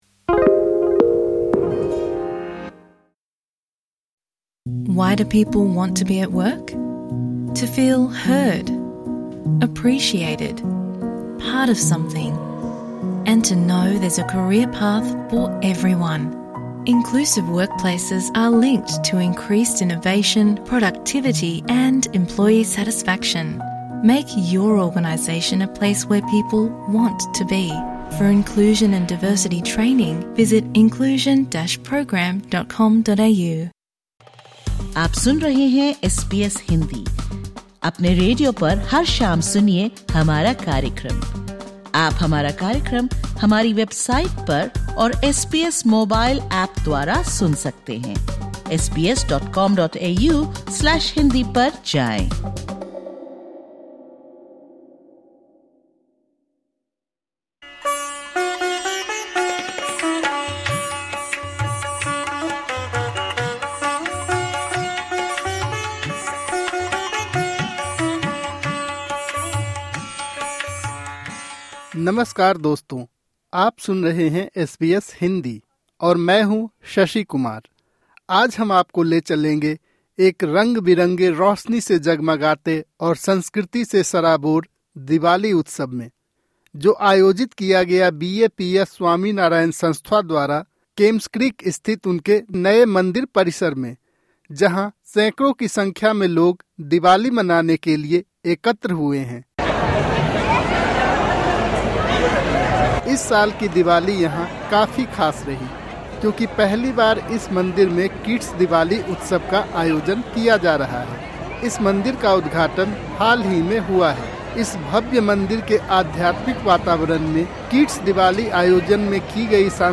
In this podcast, we share how Sydney’s BAPS Swaminarayan Hindu Mandir celebrated Diwali this year. This special episode explores the temple’s first-ever “Kids Diwali,” capturing voices from organisers, families and kids as they reflect on the festivities. From cultural performances and spiritual rituals to a finale of fireworks, the event highlights a story of light, unity, and enduring tradition within Sydney’s Hindu community.